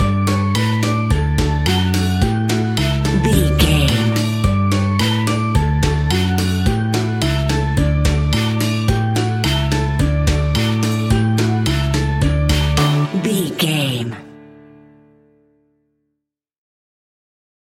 Ionian/Major
D
Fast
instrumentals
childlike
cute
kids piano